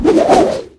fire1.ogg